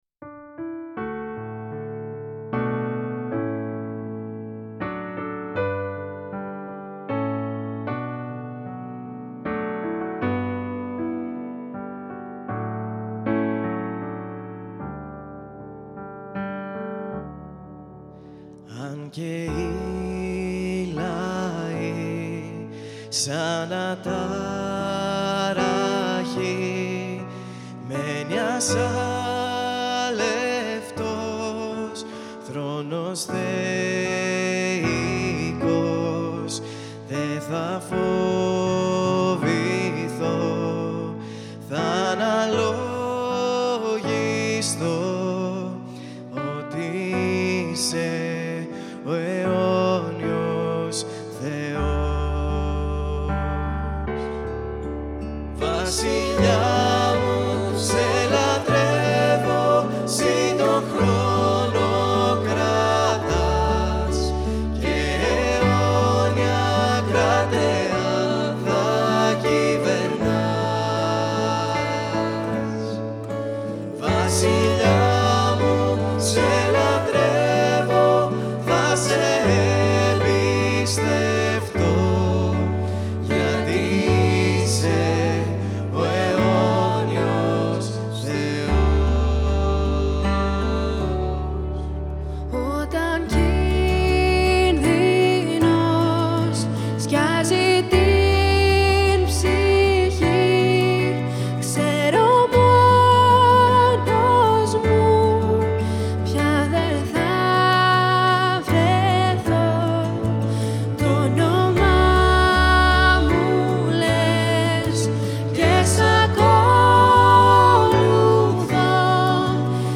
Ύμνος: “Αιώνιος Θεός”
Εδώ μπορείτε να ακούσετε τον ύμνο “Αιώνιος Θεός” σε μία απλή ηχογράφηση για να μας βοηθήσει στην εκμάθησή του.